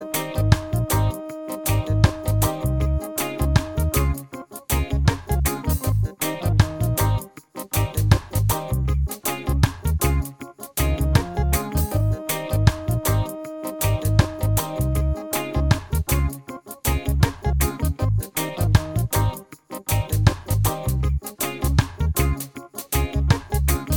Minus Guitars Reggae 4:07 Buy £1.50